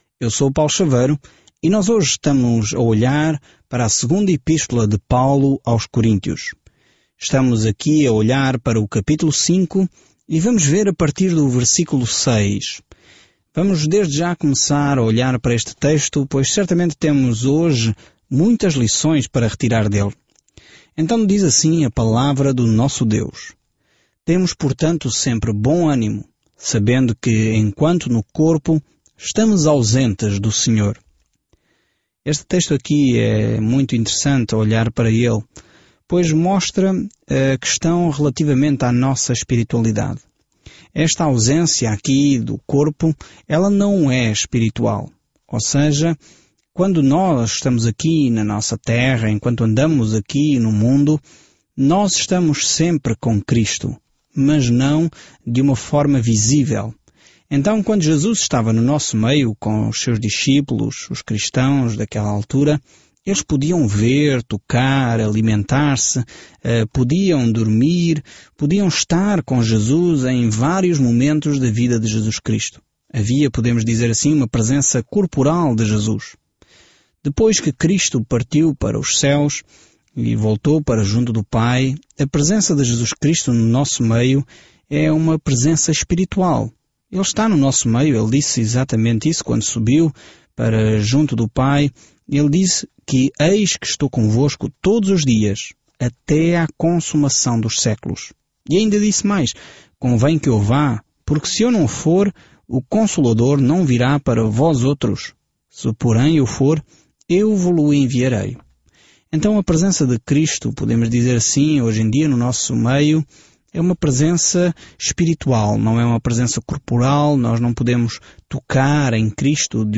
Scripture 2 Corinthians 5:6-10 Day 8 Start this Plan Day 10 About this Plan As alegrias dos relacionamentos dentro do corpo de Cristo são destacadas na segunda carta aos Coríntios enquanto você ouve o estudo em áudio e lê versículos selecionados da palavra de Deus. Viaje diariamente por 2 Coríntios enquanto ouve o estudo em áudio e lê versículos selecionados da palavra de Deus.